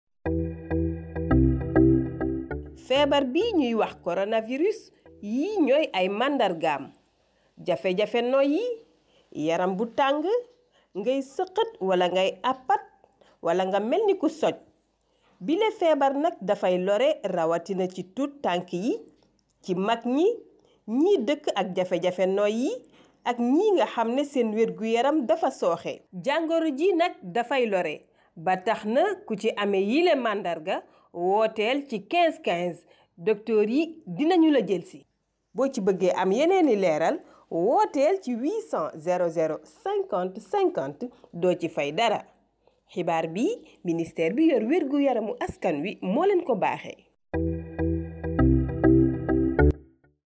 SPOT CORONAVIRUS LES SIGNES
Spot-coronavirusradiolessignes.mp3